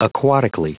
Prononciation audio / Fichier audio de AQUATICALLY en anglais
Prononciation du mot : aquatically
aquatically.wav